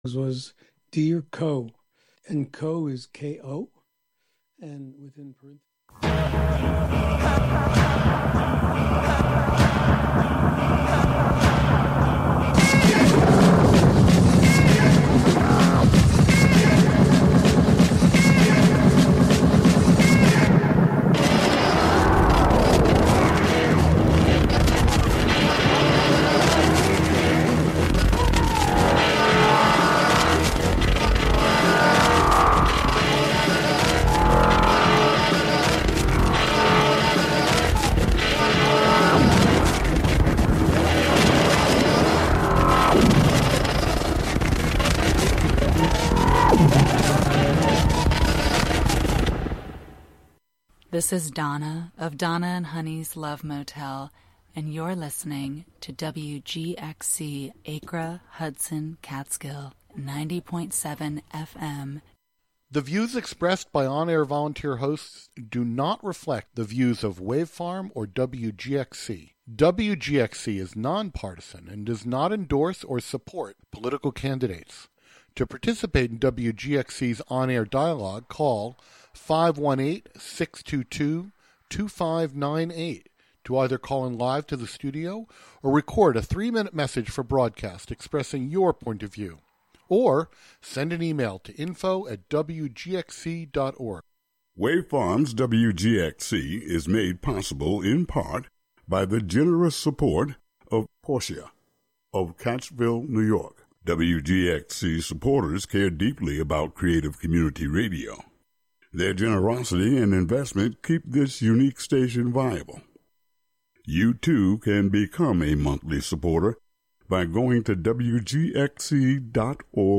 Tune into the “WGXC Afternoon Show” for local news, interviews with community leaders and personalities, reports on cultural issues, a rundown of public meetings and local and regional events, weather updates, and more about and for the community, made by volunteers in the community.